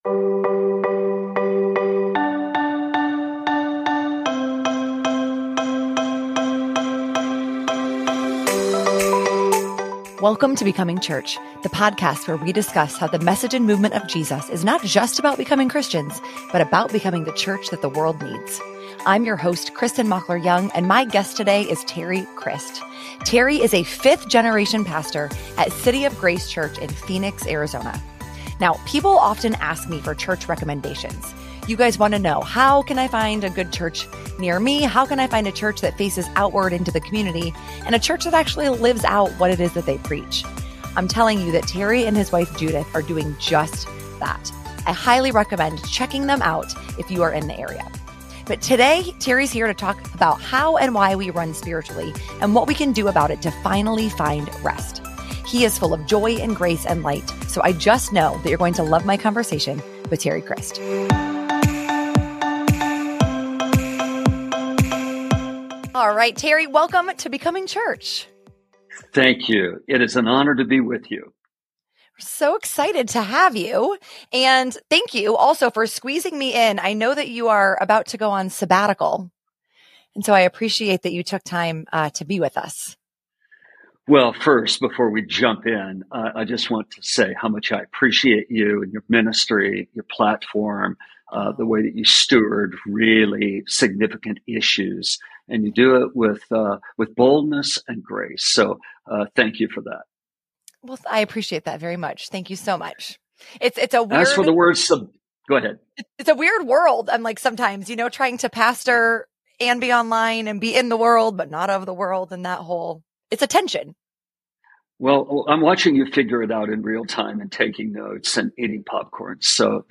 This conversation covers a multitude: from church hurt to running with MLK, from family systems theory to getting hyped up by Eminem.